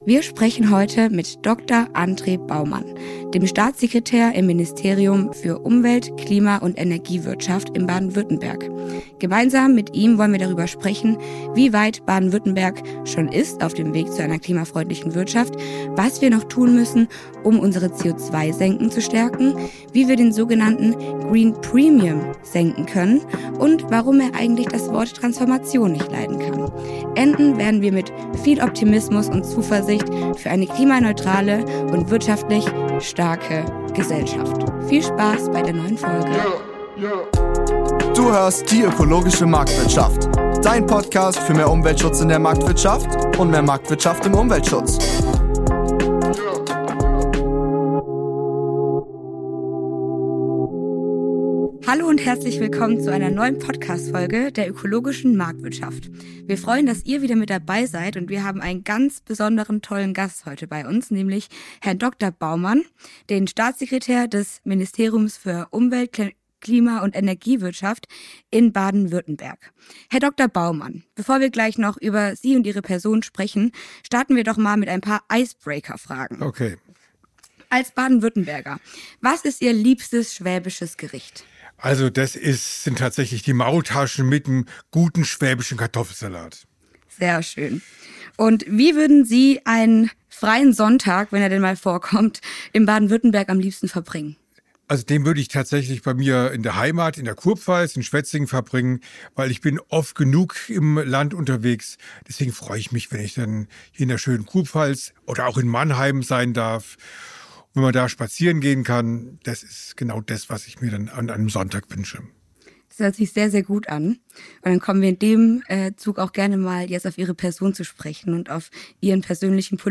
Wir sprechen heute mit Dr. Andre Baumann, Staatssekretär im Ministerium für Umwelt, Klima und Energiewirtschaft Baden-Württemberg darüber, was im Ländle getan werden muss, um den Klimaschutz marktwirtschaftlicher zu gestalten.